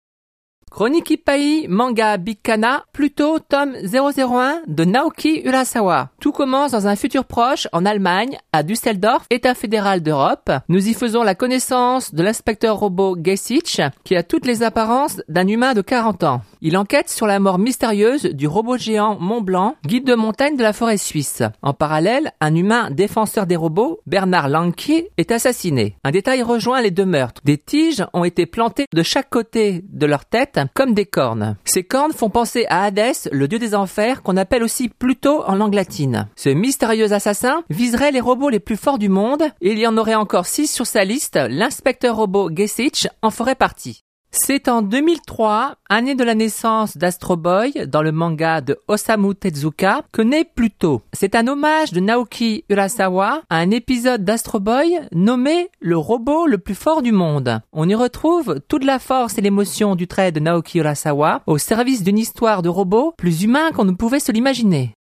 Chronique Ip-Paï - Manga Kana - "Pluto" - Tome 001 :